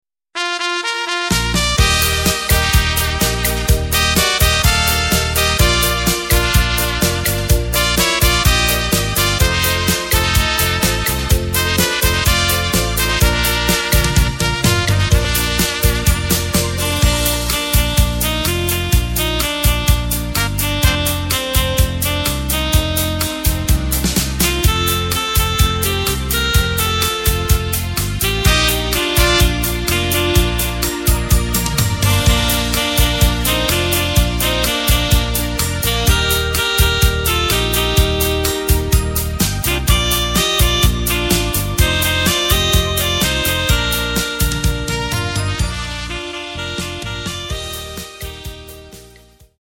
Takt:          4/4
Tempo:         126.00
Tonart:            Bb
Schlager Instrumental